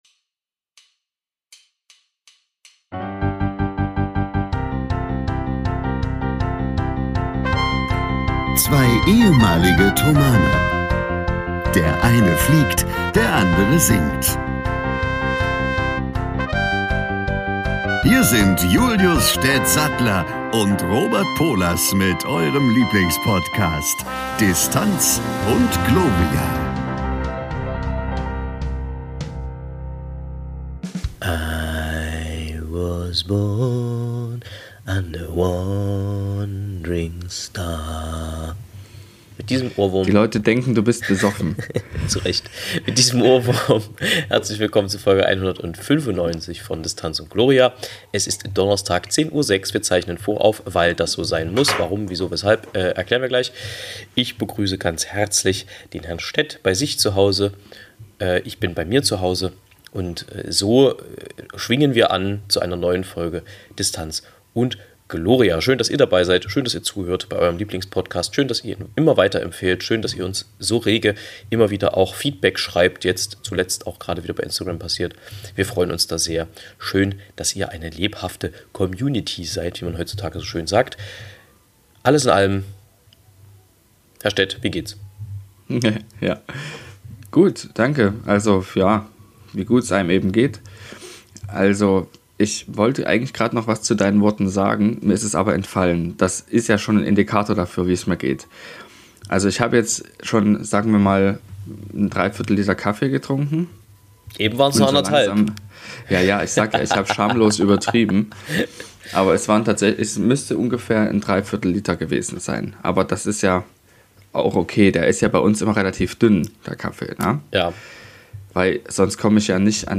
Heute ist es immer mal mit Störgeräusch gesegnet das Band, aber es tut dem Inhalt keinen Abbruch.